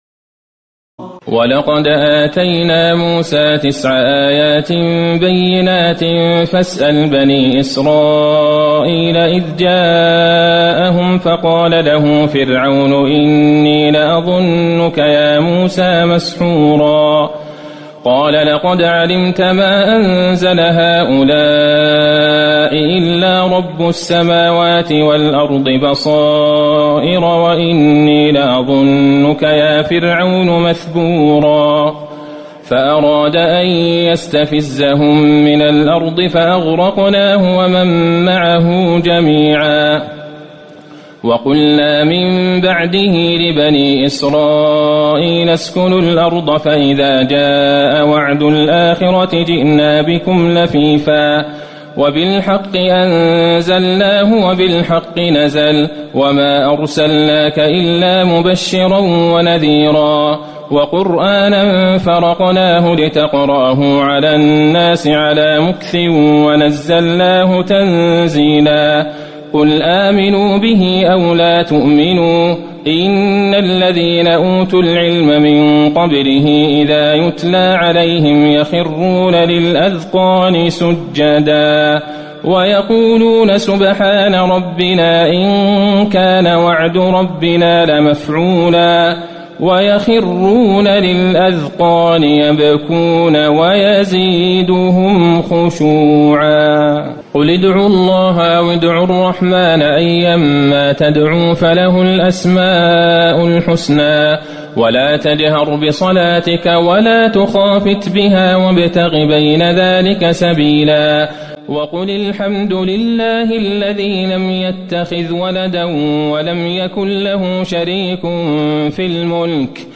تراويح الليلة الرابعة عشر رمضان 1437هـ من سورتي الإسراء (101-111) والكهف (1-82) Taraweeh 14 st night Ramadan 1437H from Surah Al-Israa and Al-Kahf > تراويح الحرم النبوي عام 1437 🕌 > التراويح - تلاوات الحرمين